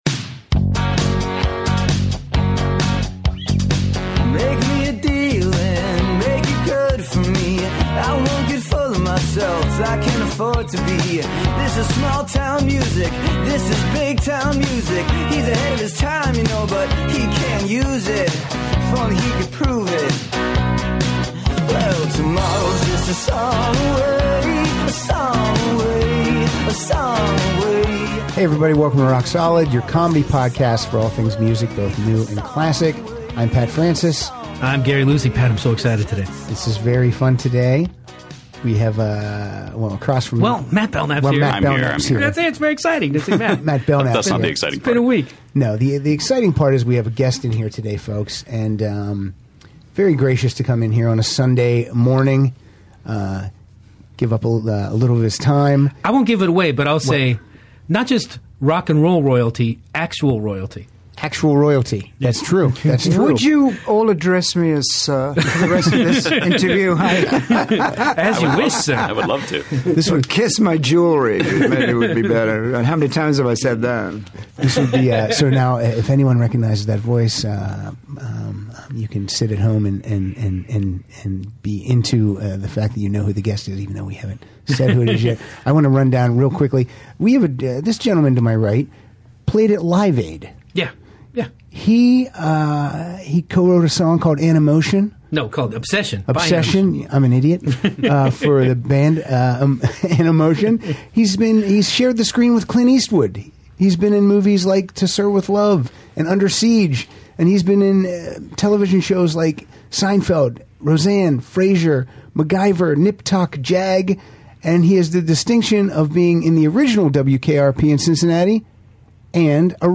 chat show